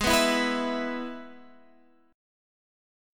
Abm#5 chord